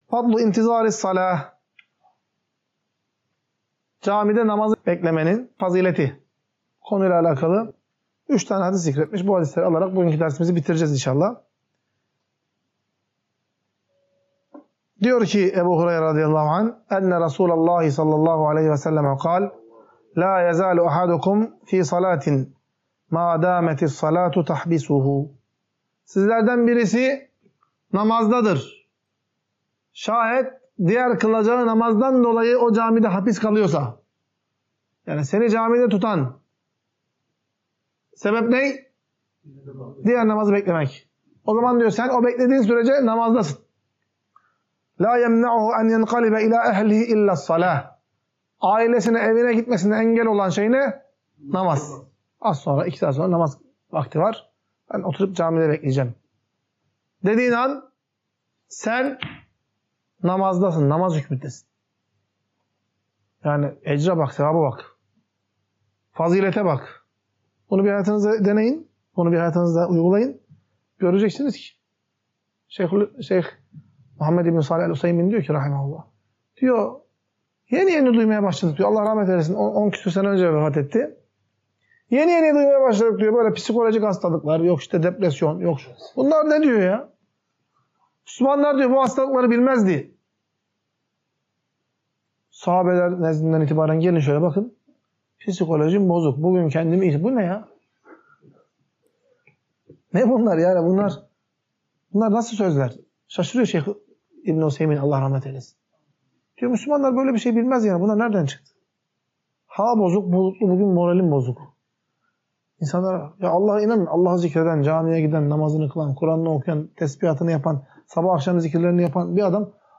Ders - 11.